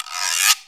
LONG GUILO.wav